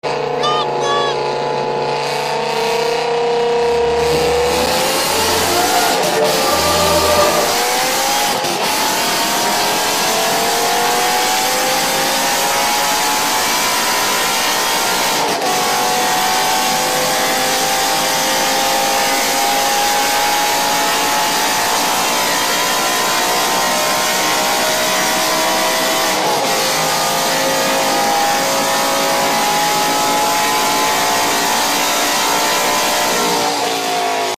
M5 E60 V10 Pure Sound Sound Effects Free Download